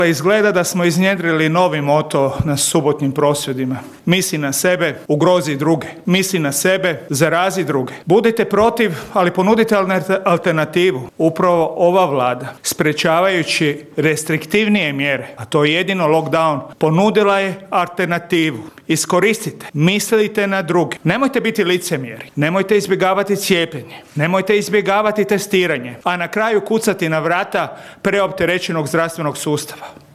ZAGREB - Od jučer su preminule 62 osobe, a novozaraženih koronavirusom je 4.926. A jedna od tema sastanka vladajuće većine u Banskim dvorima bila je upravo borba protiv pandemije i kako se nositi s onima koji odbijaju COVID potvrde. Ministar zdravstva Vili Beroš: